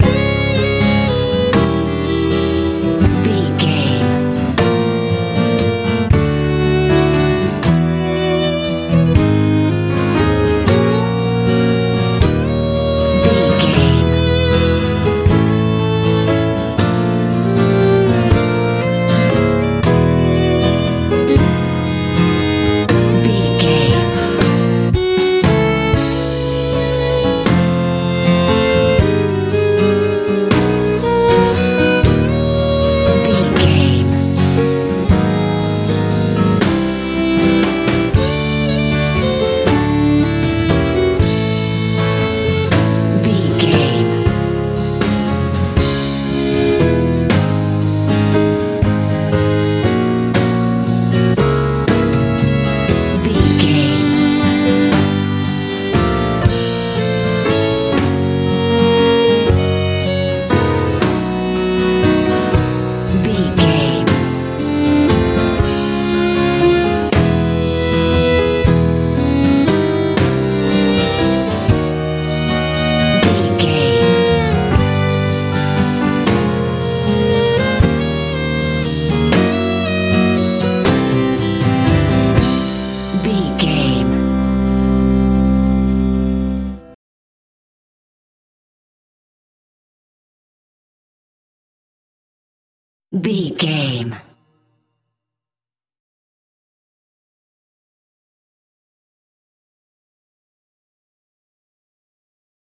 lite pop feel
Ionian/Major
D♭
calm
piano
violin
acoustic guitar
bass guitar
drums
80s
90s